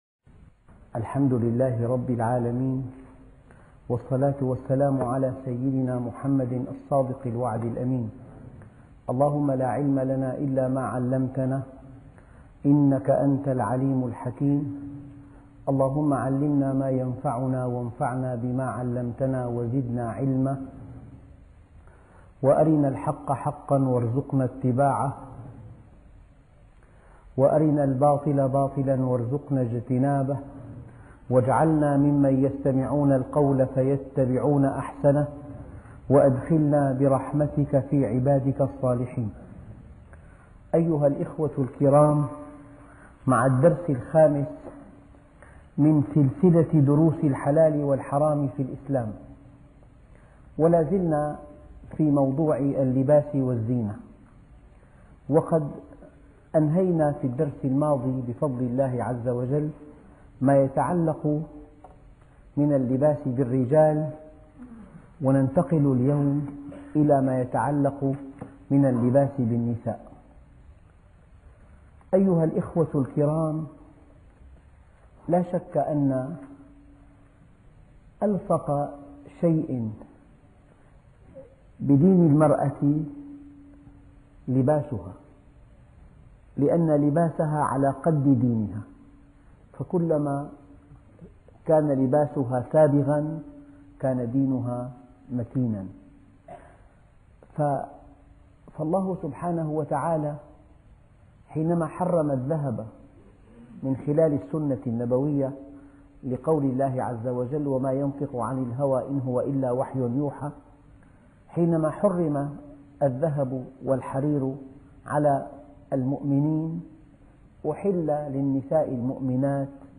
الدرس الخامس لباس المرأة وزينتها -الفقه الإسلامي عن الحلال والحرام - الشيخ محمد راتب النابلسي